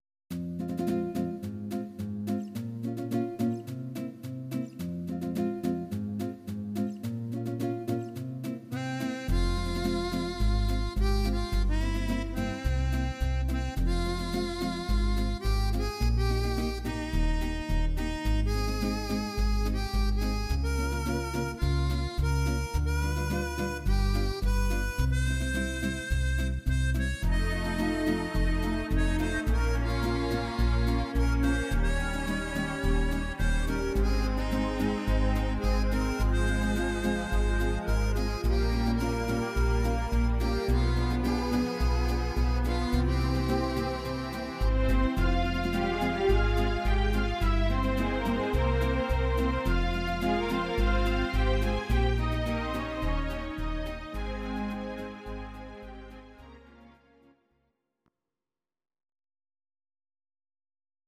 Audio Recordings based on Midi-files
Oldies, Musical/Film/TV, 1960s